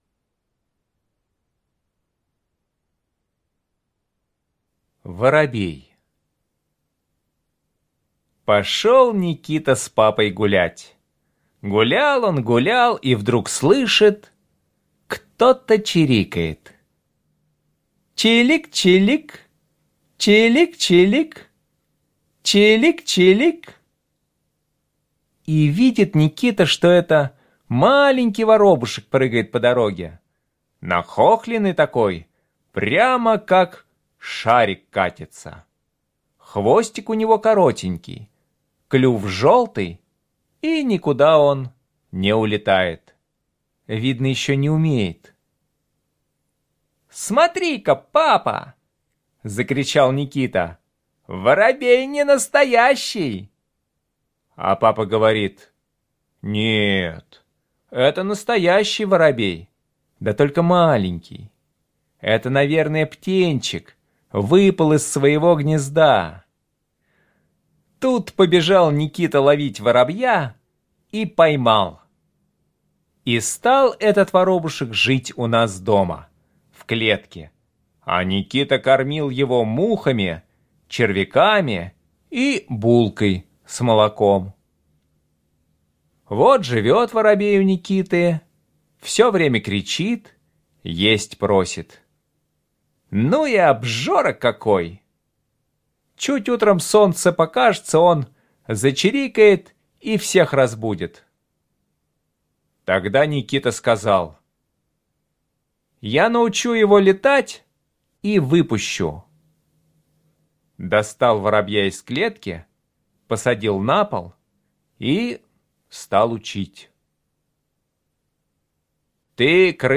Слушайте Воробей - аудио рассказ Чарушина Е.И. Рассказ про маленького воробья, который выпал из гнезда и еще не умел летать.